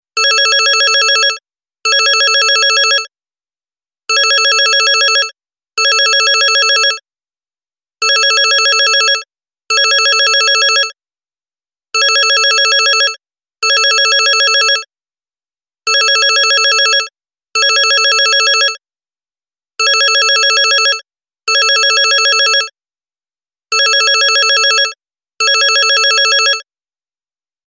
domashnii-telefon_24619.mp3